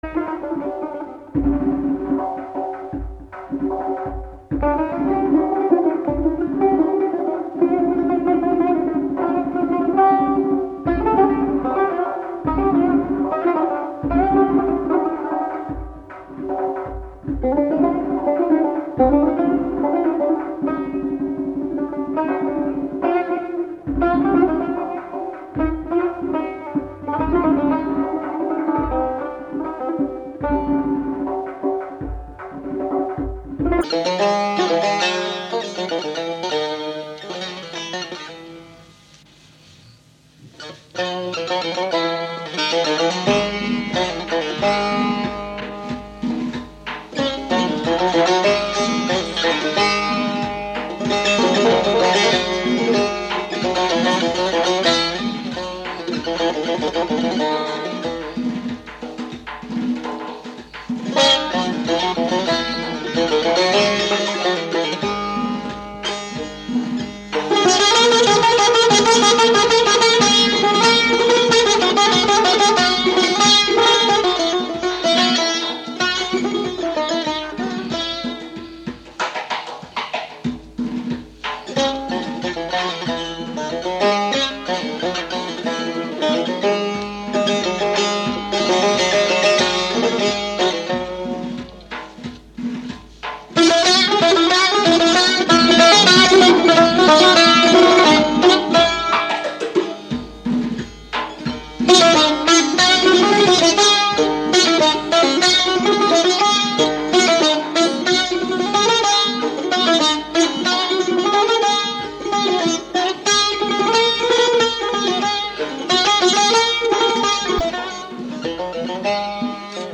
تار